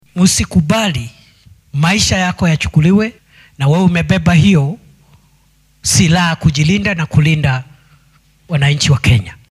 Arrintan ayuu ka sheegay munaasabad ka dhacday deegaanka Embakasi ee ismaamulka Nairobi oo lagu xusuusanayay saraakiishii ammaanka ee geeriyooday iyagoo qaranka u adeegaya.